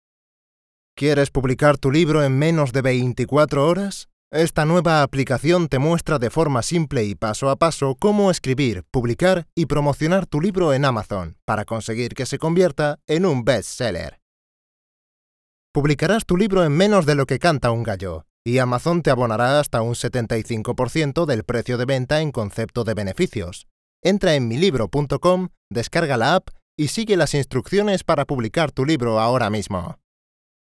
Deep voice, spanish speaker, young voice
kastilisch
Sprechprobe: Werbung (Muttersprache):
I have a very remarkable and unique voice, with which I can change several types of records depending on the project, adapting perfectly to each of these.